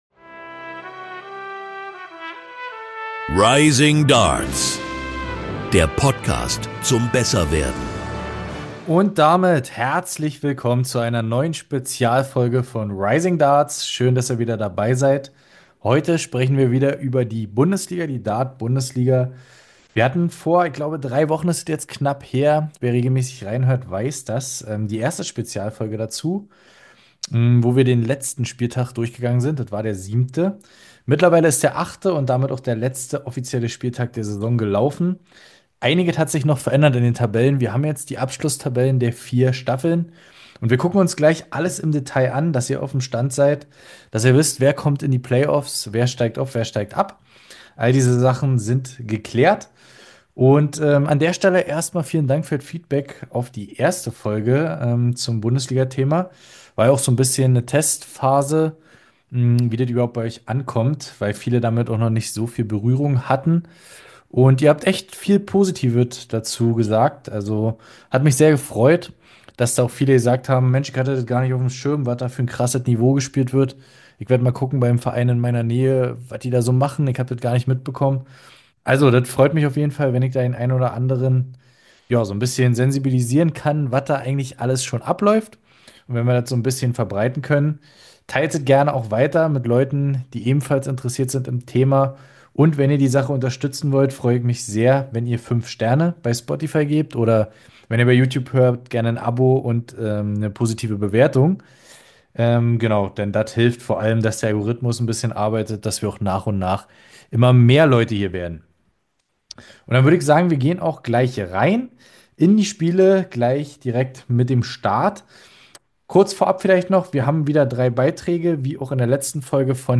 Wir schauen uns die Ergebnisse im Detail an und sprechen über die endgültigen Tabellen. Mit Live-Berichten von mehreren Bundesligaspielern.